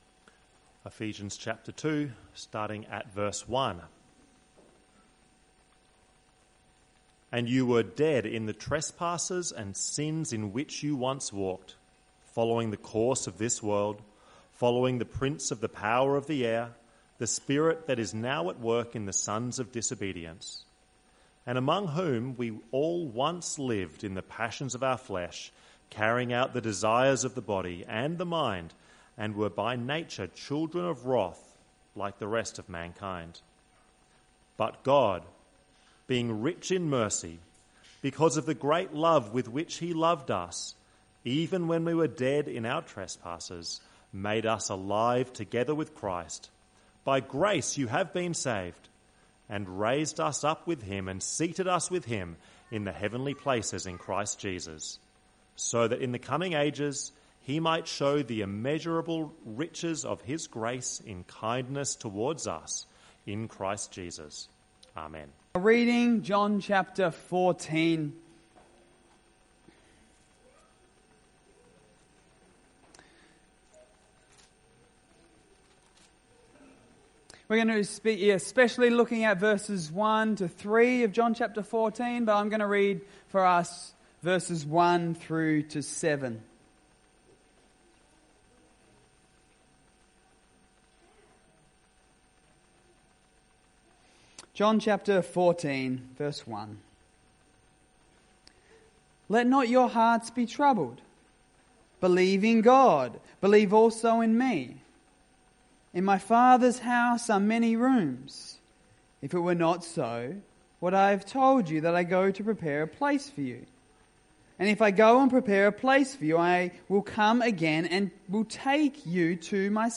Evening Service